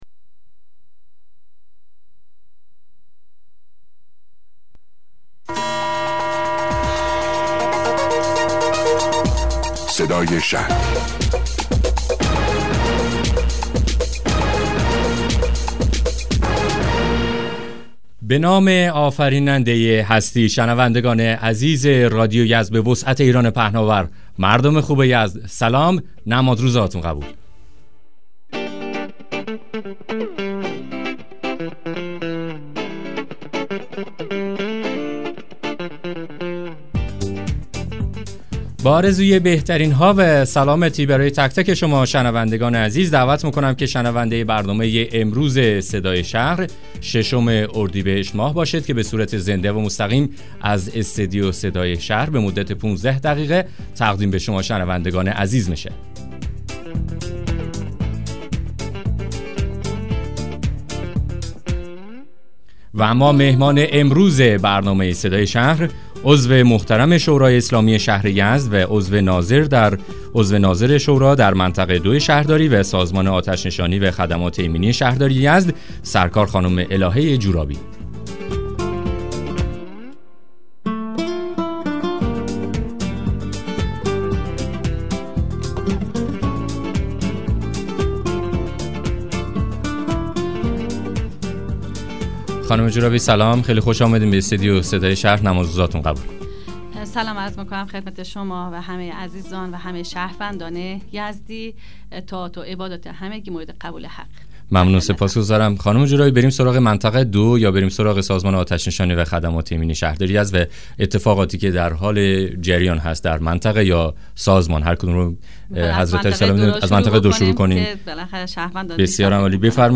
مصاحبه رادیویی برنامه صدای شهر با حضور الهه جورابی عضو شورای اسلامی شهر یزد